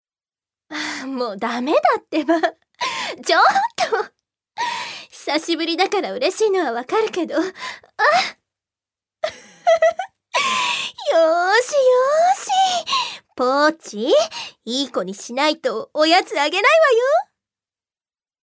女性タレント
セリフ５